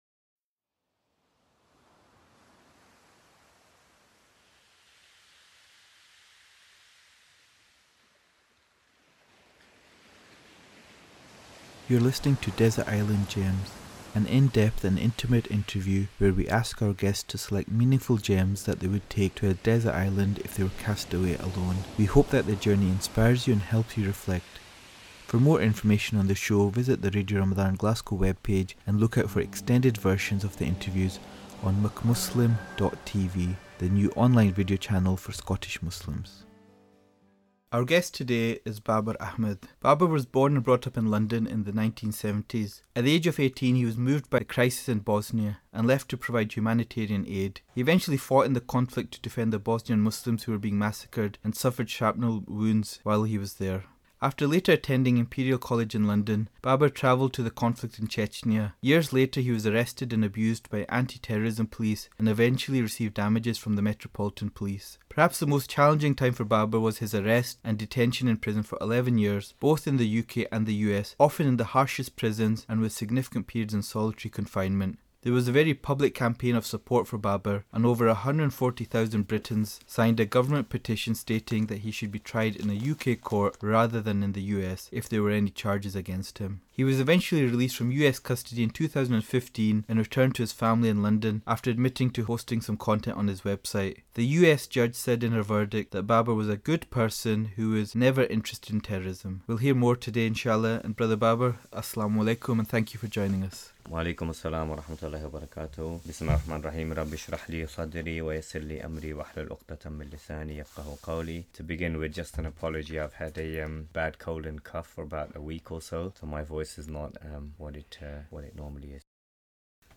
In this radio interview broadcast on Radio Ramadhan Glasgow’s Desert Island Gems programme on 05 June 2017, I share some of the meaningful gems that I would take with me if I was stranded on a desert island.
Apologies if I speak a little fast in the interview. I think the editors speeded it up to fit it all in.